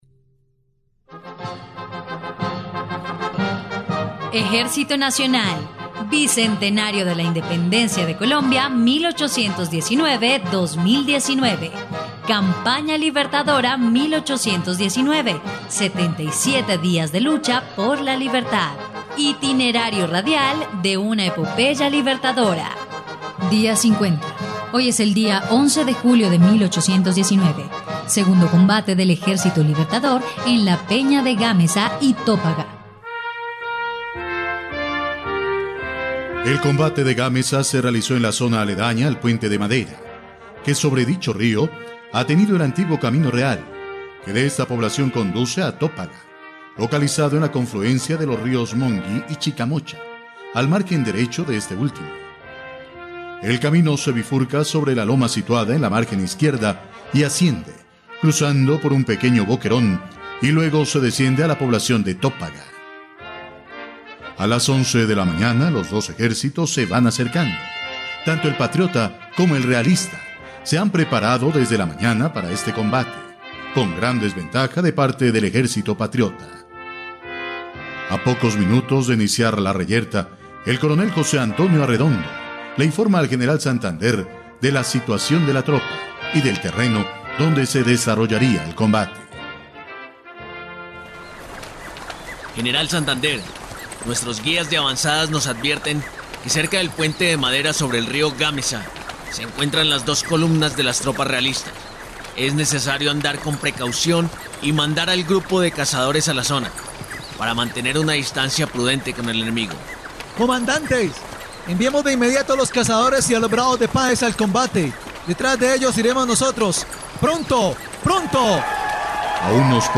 dia_50_radionovela_campana_libertadora.mp3